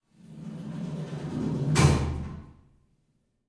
DoorOpen.ogg